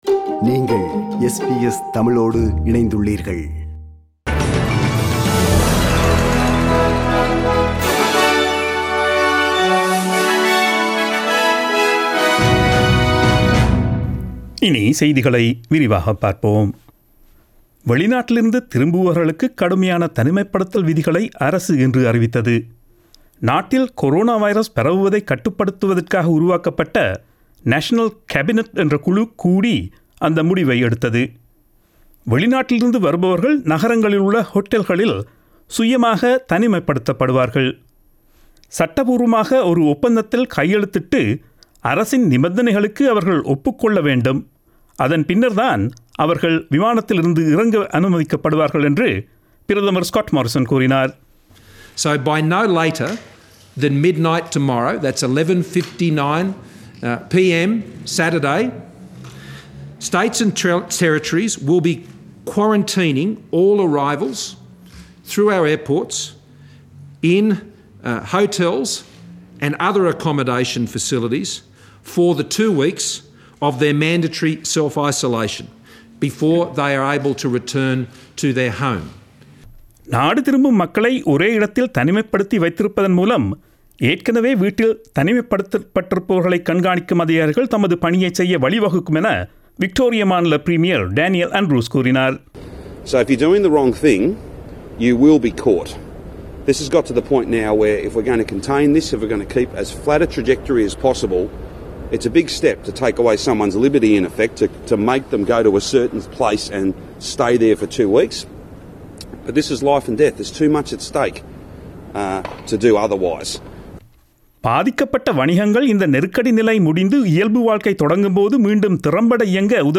Australian news bulletin aired on Friday 27 March 2020 at 8pm.